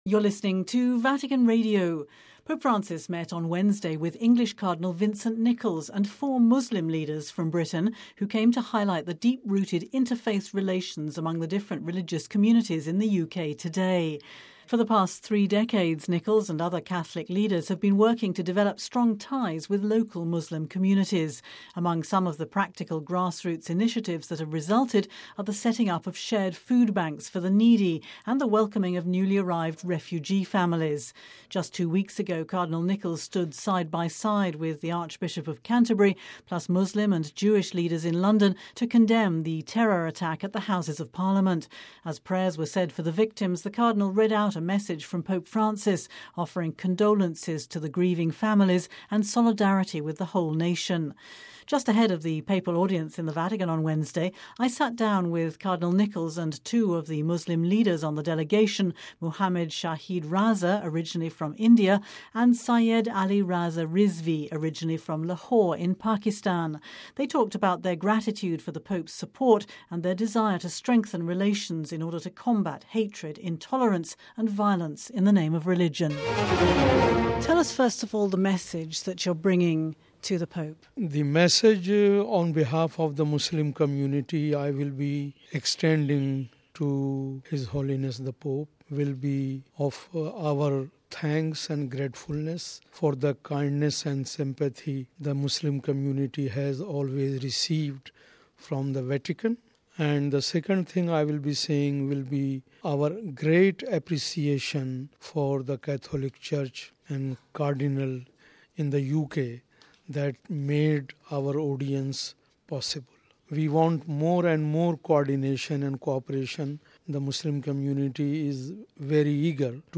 (Vatican Radio) Pope Francis met on Wednesday with English Cardinal Vincent Nichols and four Muslim leaders from Britain who came to highlight the deep-rooted interfaith relations among the different religious communities in the UK today.